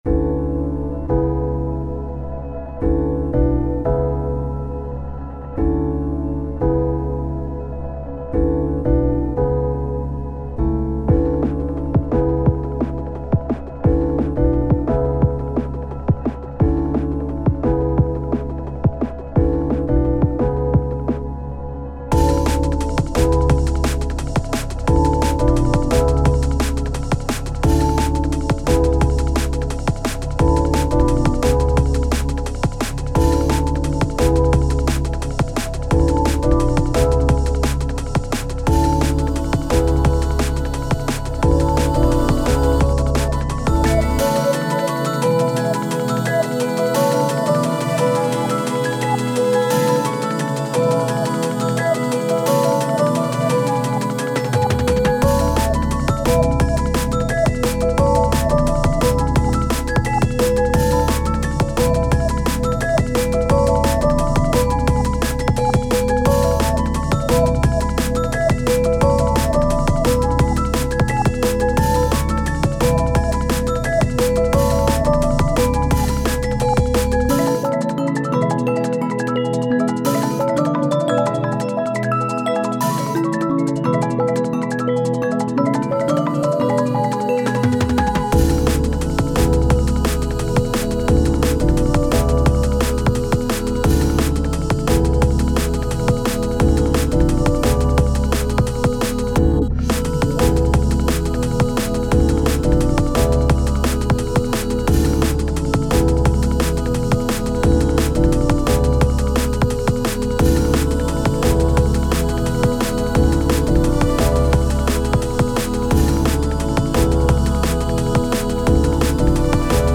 アンビエントらしい雰囲気とDnBを掛け合わせた楽曲。
タグ: DnB おしゃれ アンビエント 切ない 幻想的 コメント: アンビエントらしい雰囲気とDnBを掛け合わせた楽曲。